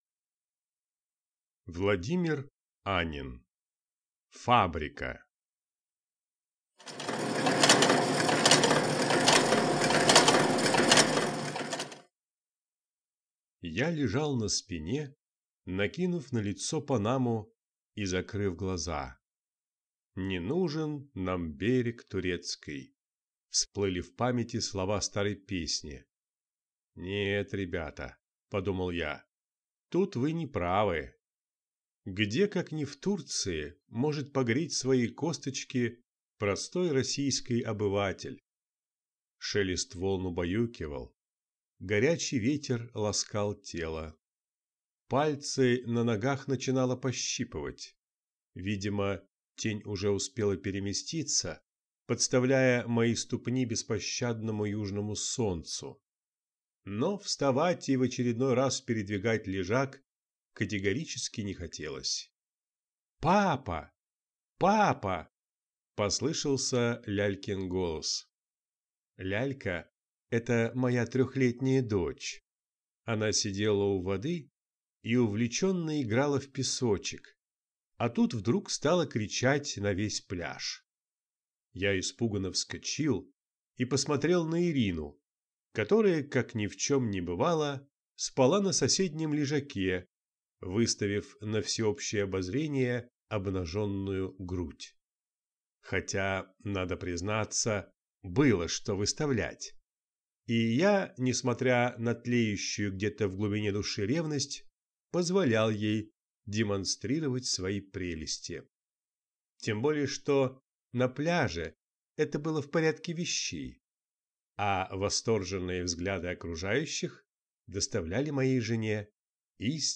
Aудиокнига Фабрика